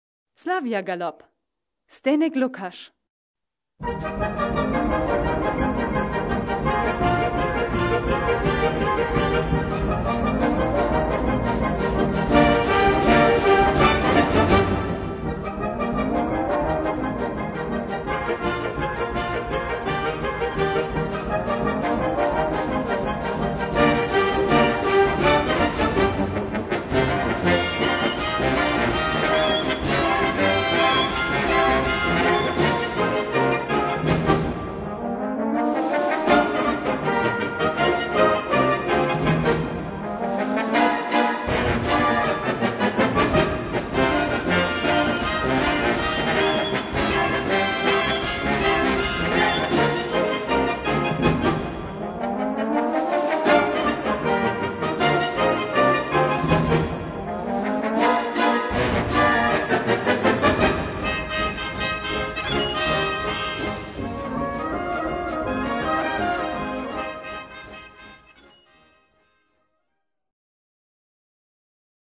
Gattung: Galopp
Besetzung: Blasorchester